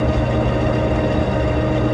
1 channel
LFTLOOP1.mp3